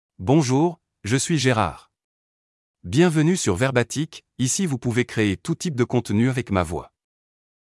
MaleFrench (Belgium)
Voice sample
Male
French (Belgium)